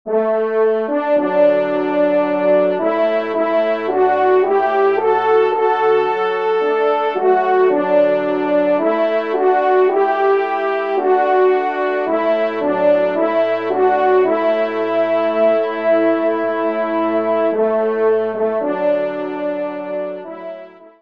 Genre : Musique Religieuse pour Trois Trompes ou Cors
Pupitre 1°Trompe